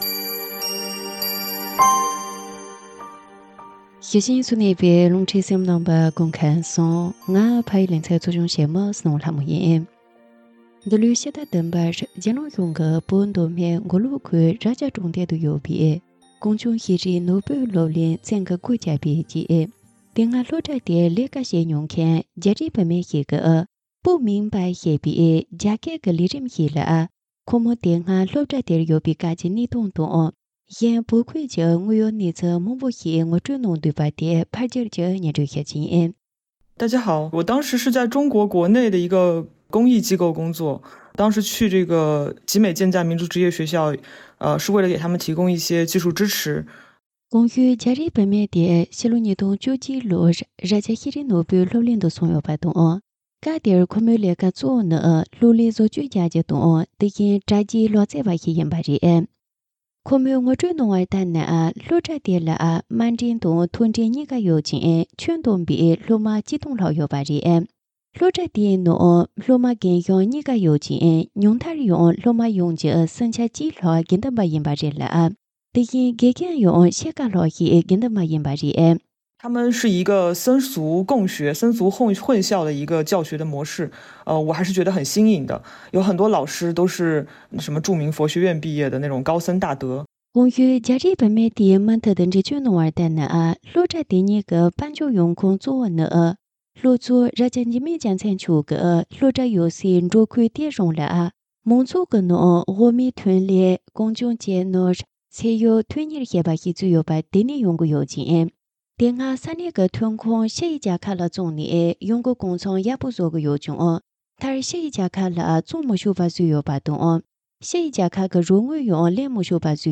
ཕབ་བསྒྱུར་དང་སྙན་སྒྲོན་ཞུས་པར་གསན་རོགས།